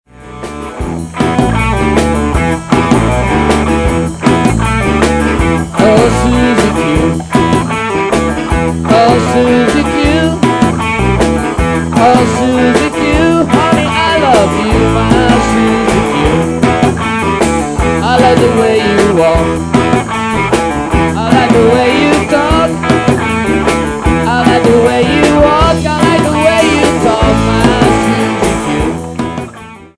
Estratti di brani registrati in sala prove